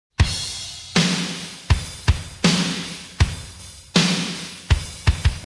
Вложения Rock_Snare_.mp3 Rock_Snare_.mp3 127,9 KB · Просмотры: 117